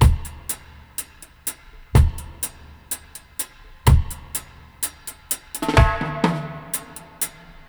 62-FX-01.wav